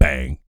BS BANG 01-R.wav